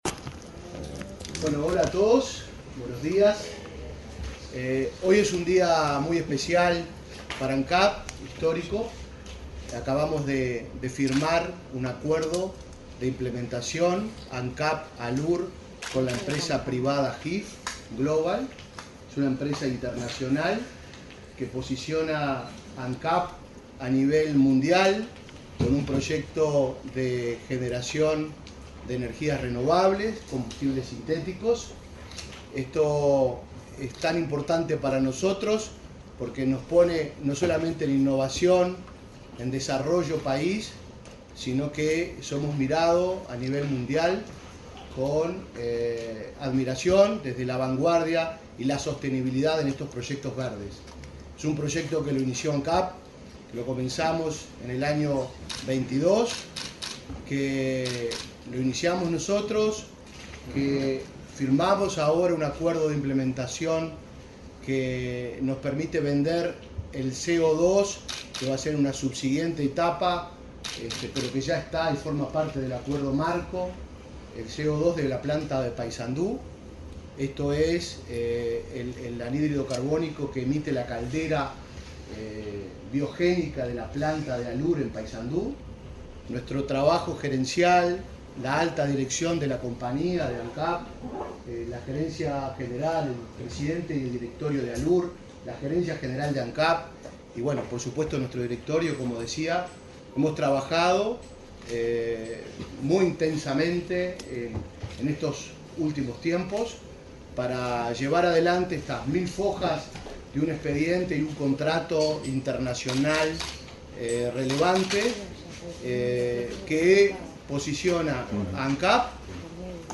Palabras de autoridades en acto en Ancap
El presidente interino de Ancap, Diego Durand, y la ministra de Industria, Elisa Facio, participaron en la firma de un acuerdo entre Alcoholes del